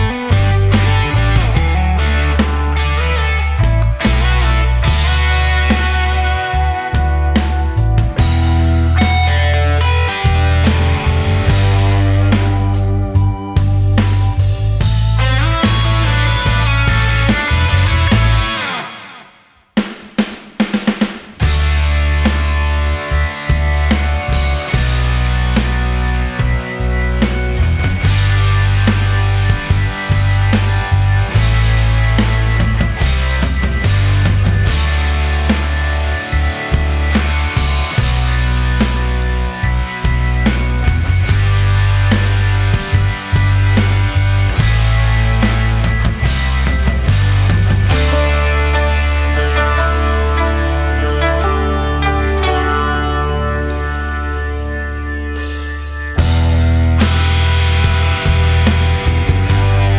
no Backing Vocals Rock 5:39 Buy £1.50